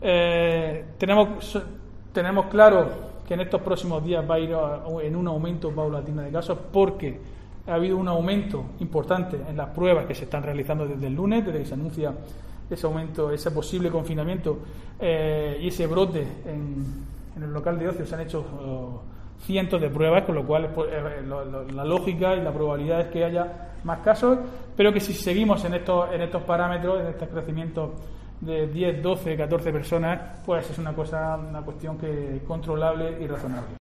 Diego José Mateos, alcalde Lorca